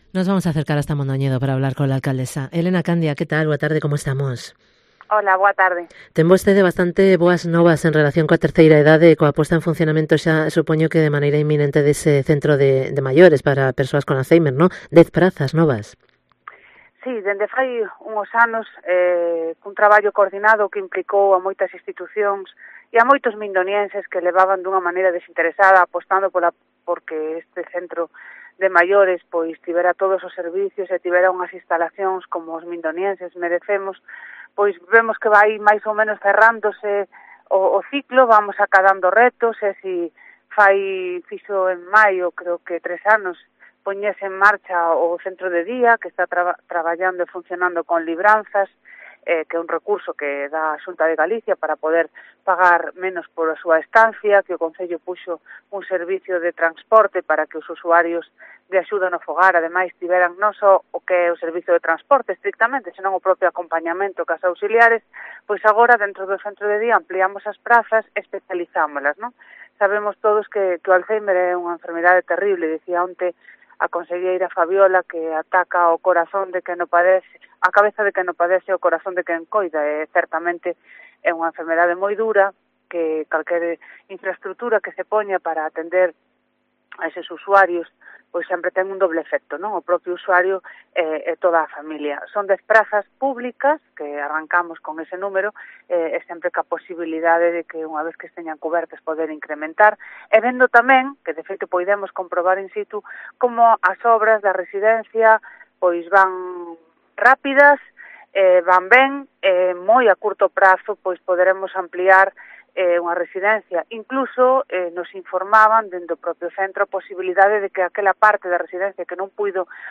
ENTREVISTA con ELENA CANDIA, alcaldesa de Mondoñedo